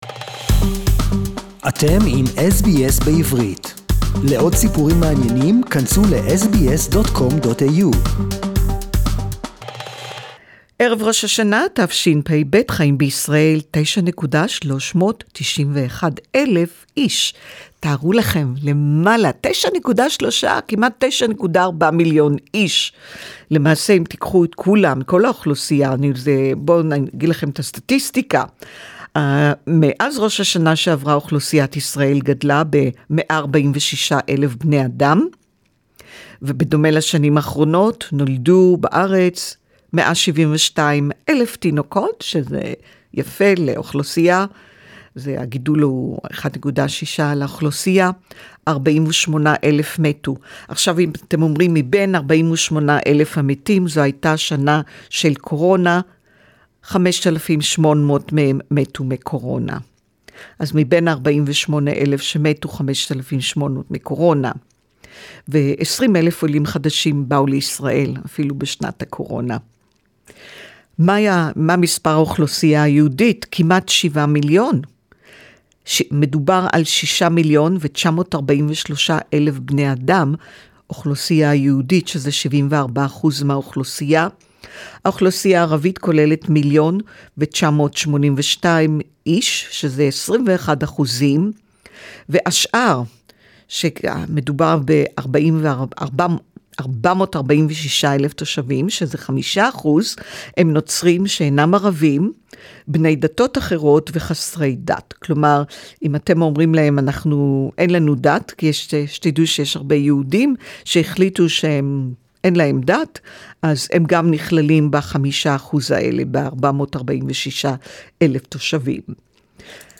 SBS Report in Hebrew: Israel's population reaches 9.4m & Go Get Vaccinated